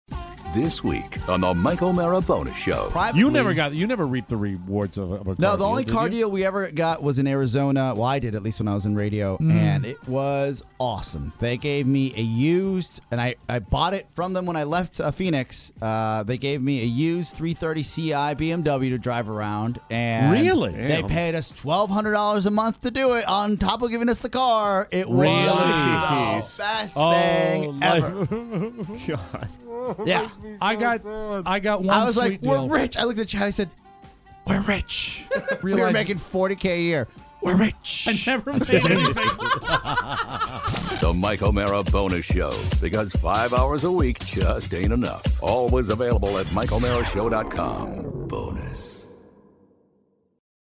A rousing but unexpected Christmas song to get you in the holiday spirit! And of course cars, cars and cars.